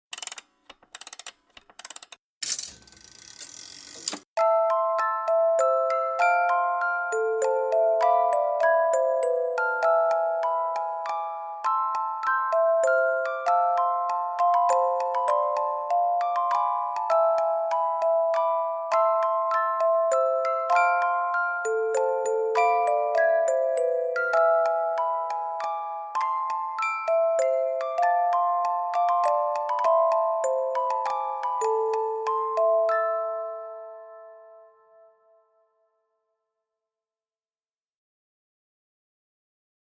Здесь собраны лучшие композиции с нежным механическим звучанием, которые подойдут для релаксации, творчества или создания особой атмосферы.
Запуск мелодии на шкатулке или коробке для чая с механизмом воспроизведения звука